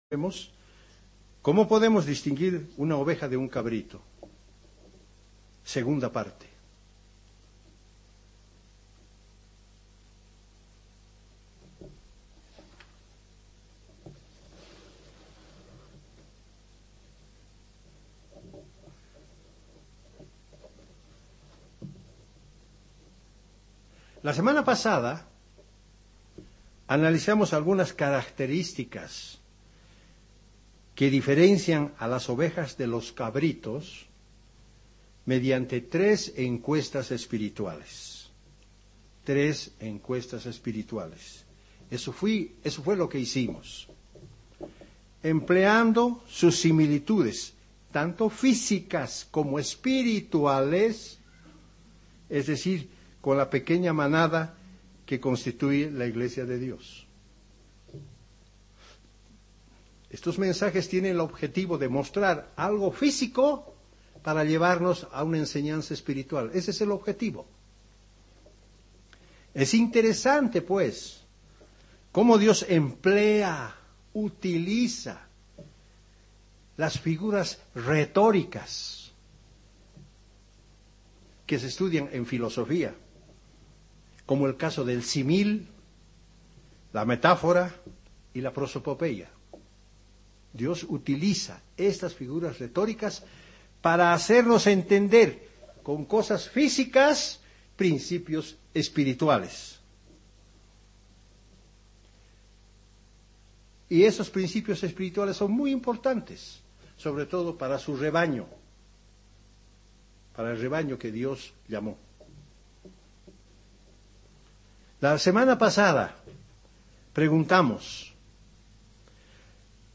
Given in La Paz